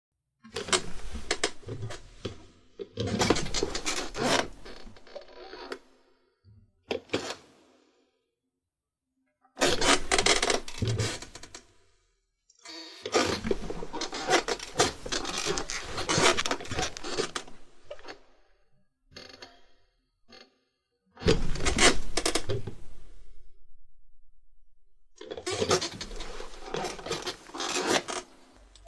Tiếng Ghế Kêu cót két, kọt kẹt…
Thể loại: Tiếng đồ vật
Âm thanh ma sát khô khốc trầm đục, lúc chói tai, gợi hình ảnh chiếc ghế cũ kỹ đang lay chuyển.
tieng-ghe-keu-cot-ket-kot-ket-www_tiengdong_com.mp3